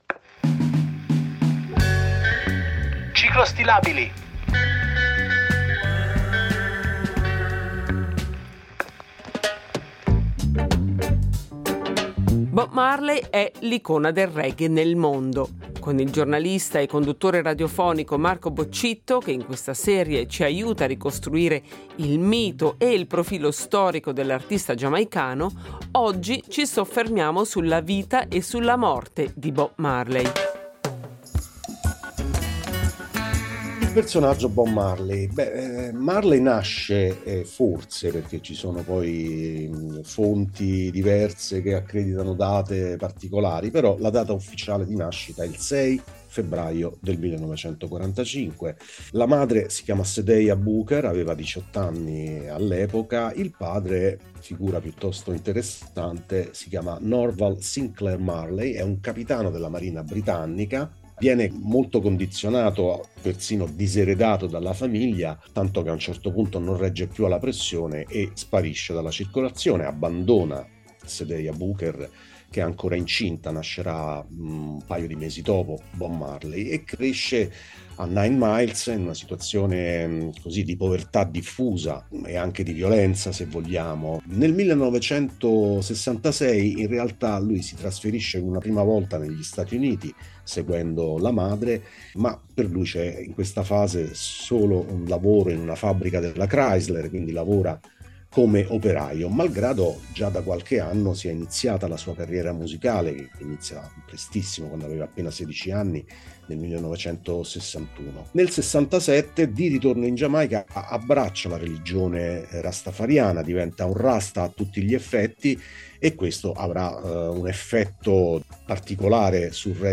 ha intervistato il giornalista e conduttore radiofonico